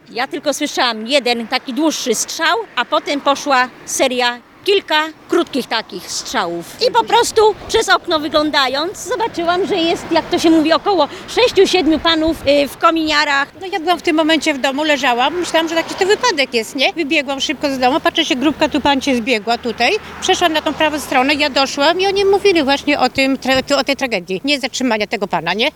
swiadkowie zdarzenia.mp3